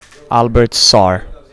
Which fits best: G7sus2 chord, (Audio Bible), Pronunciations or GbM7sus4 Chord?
Pronunciations